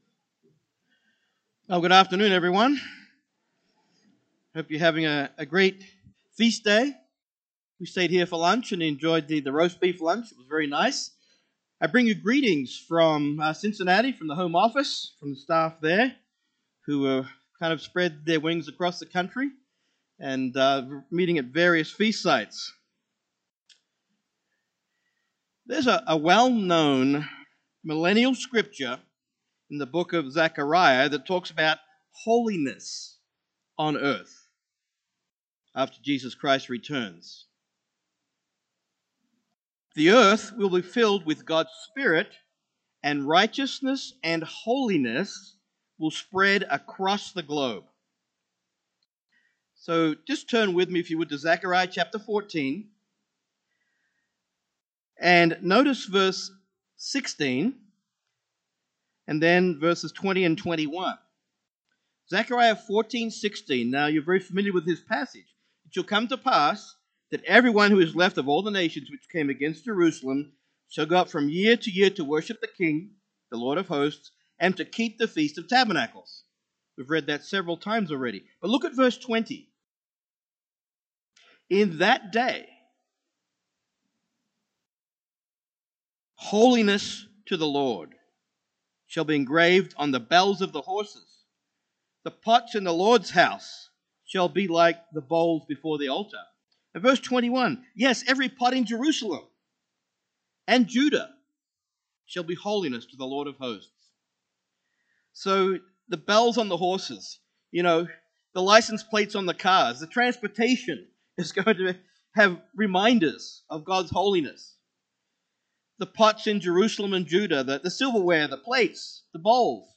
A split sermon given on the Eighth Day at Glacier Country, Montana, 2020, looking more closely at the meaning of this Holy Day—and in particular the concept of holiness permeating all of creation. The Church is preparing to meet the Bridegroom by becoming holy and cleansed.
This sermon was given at the Glacier Country, Montana 2020 Feast site.